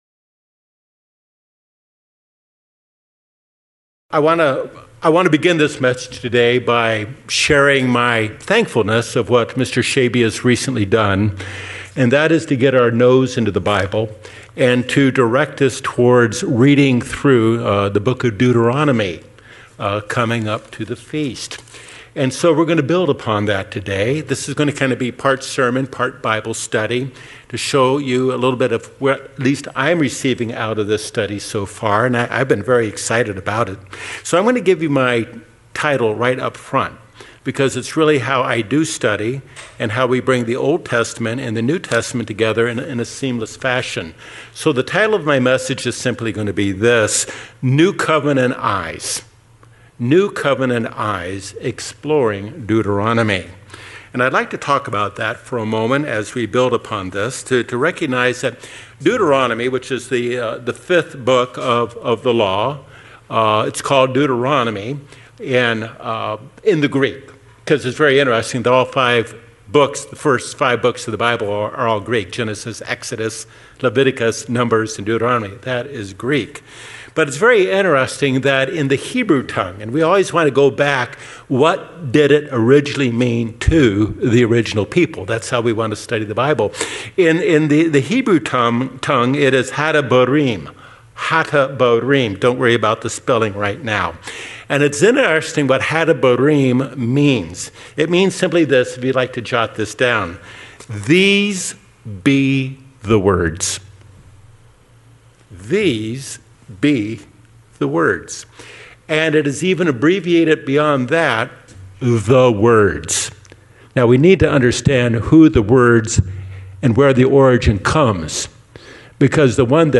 This is going to kind of be part sermon, part Bible study to show you a little bit of what at least I'm receiving out of this study so far, and I've been very excited about it.